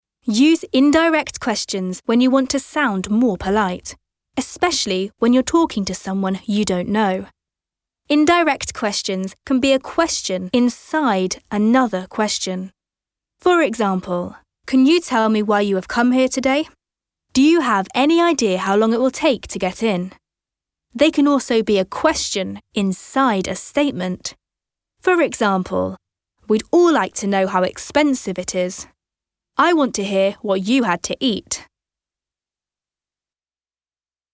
Comercial, Joven, Cool, Versátil, Amable
E-learning